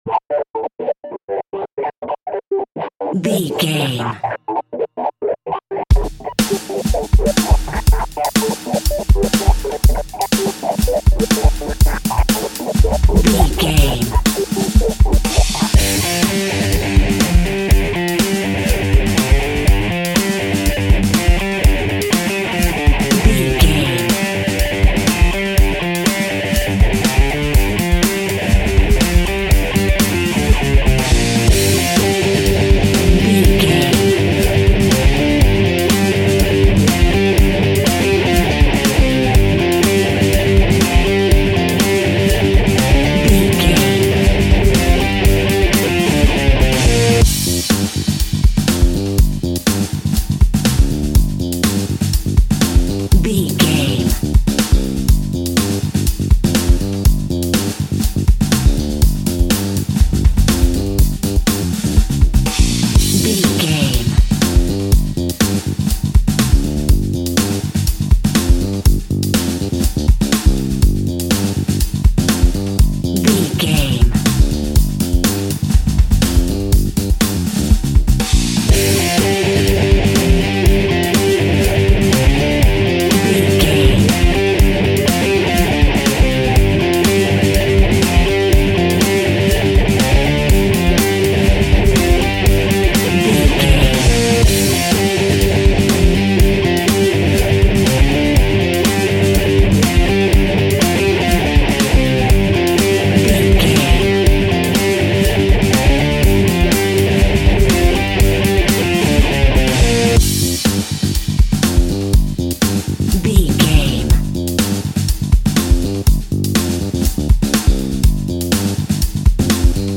Aeolian/Minor
hard rock
blues rock
distortion
instrumentals
Rock Bass
heavy drums
distorted guitars
hammond organ